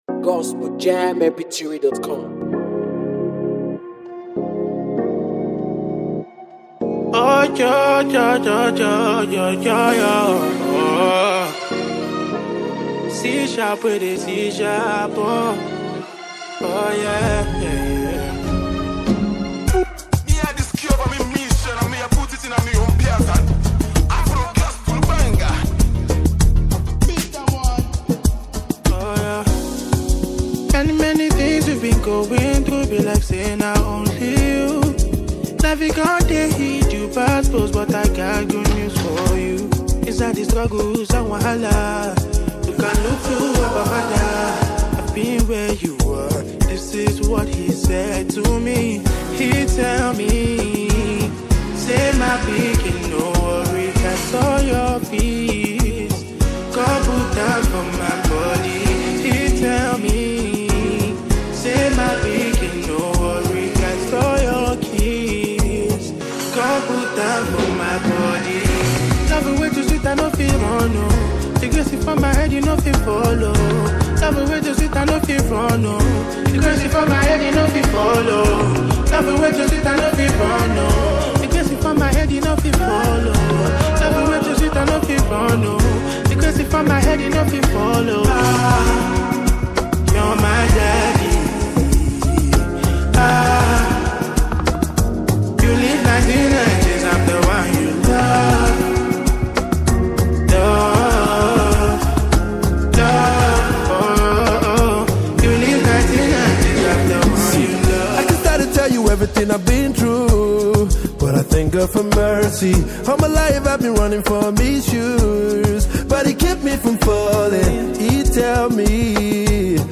Afro gospel